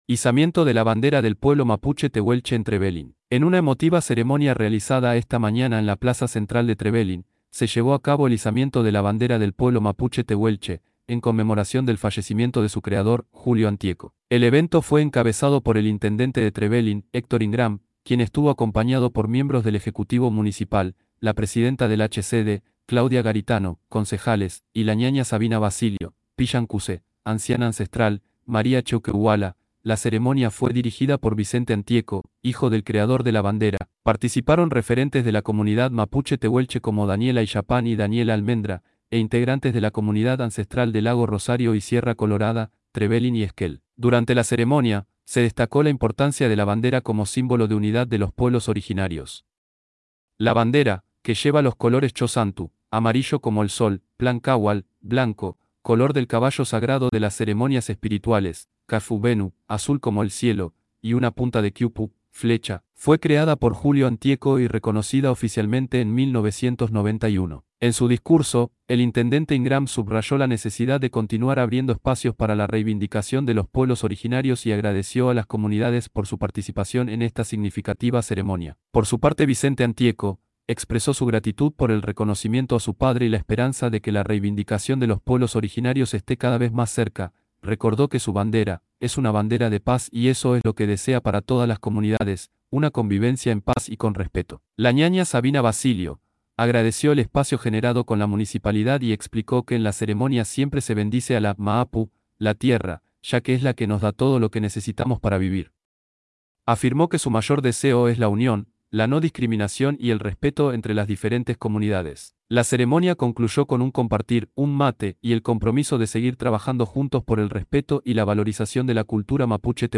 En su discurso, el Intendente Ingram subrayó la necesidad de continuar abriendo espacios para la reivindicación de los pueblos originarios y agradeció a las comunidades por su participación en esta significativa ceremonia.